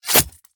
flesh1.ogg